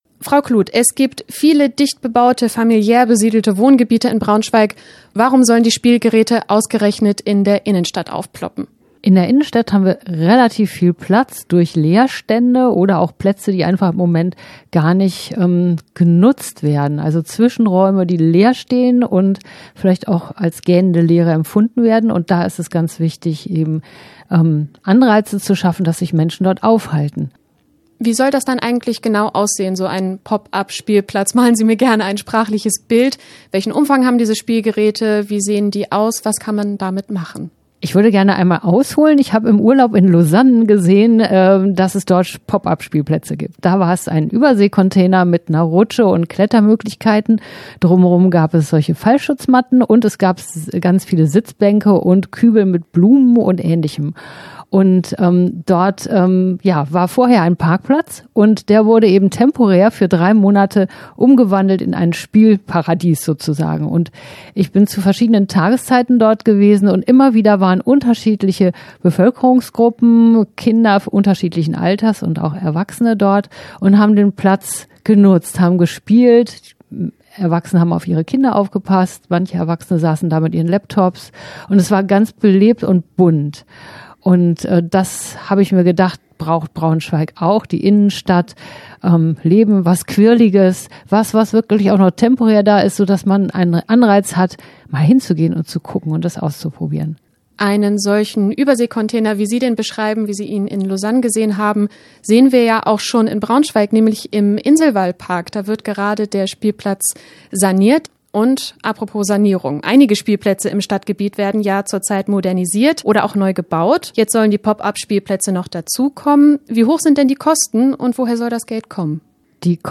Pop-up-Spielplätze in Braunschweig: Die Initiatorin und Grünen-Ratsfrau Sabine Kluth im Gespräch - Okerwelle 104.6